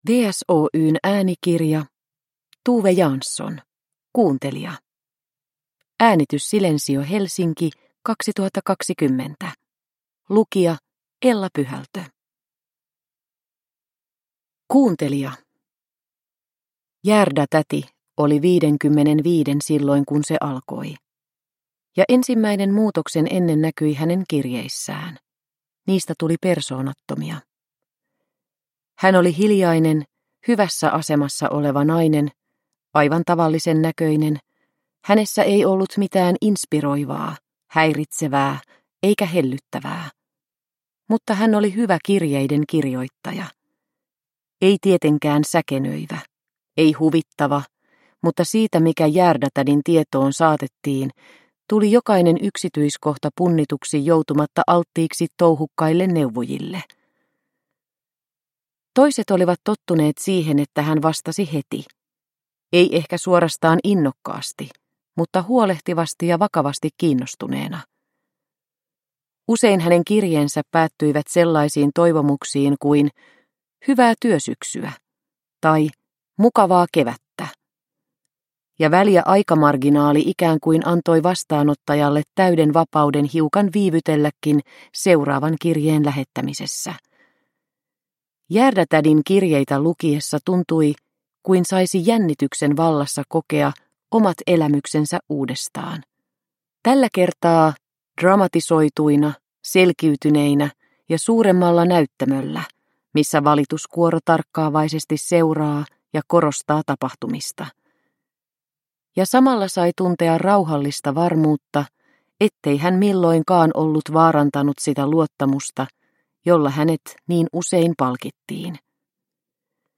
Kuuntelija – Ljudbok – Laddas ner